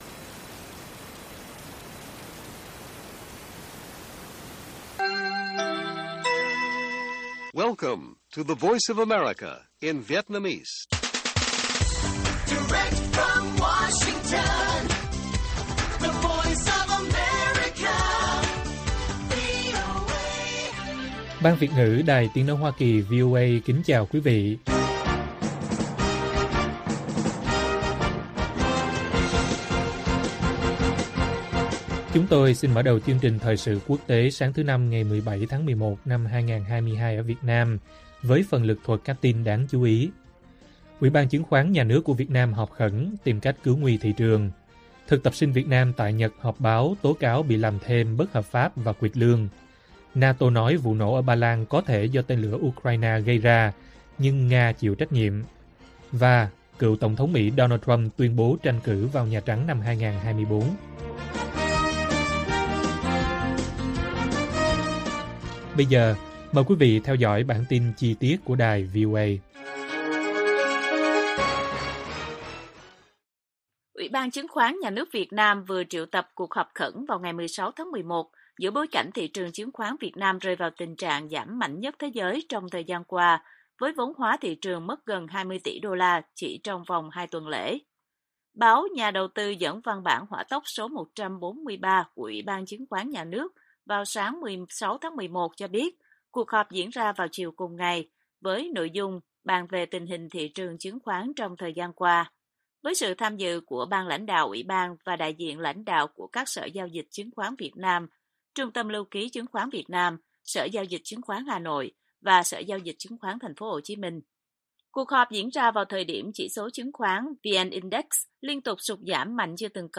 NATO nói vụ nổ ở Ba Lan có thể do tên lửa Ukraine gây ra, nhưng Nga chịu trách nhiệm - Bản tin VOA